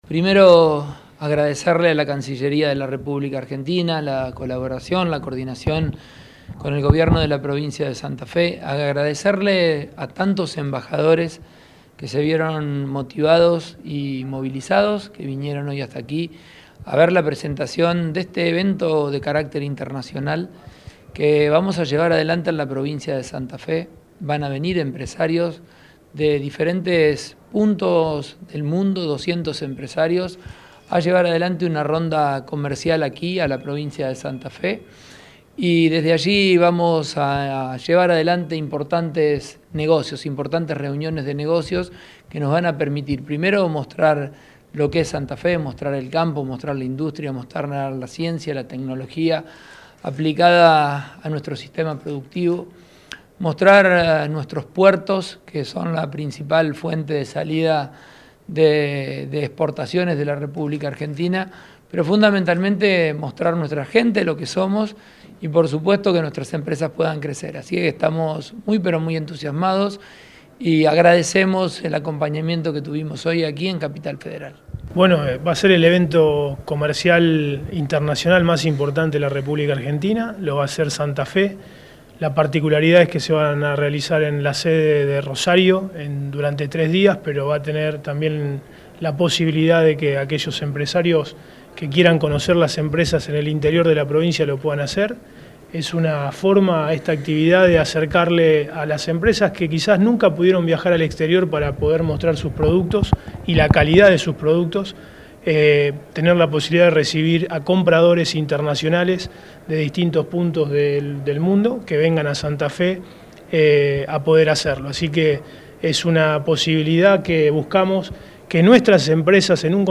Durante la actividad, llevada a cabo en el Salón Libertador del Palacio San Martín de la Cancillería Argentina, participaron empresarios, emprendedores, representantes diplomáticos y expertos en comercio exterior para conocer el alcance de la iniciativa, que se realizará del 3 al 5 de septiembre de 2024 en las instalaciones de La Fluvial en Rosario.
Declaraciones de Pullaro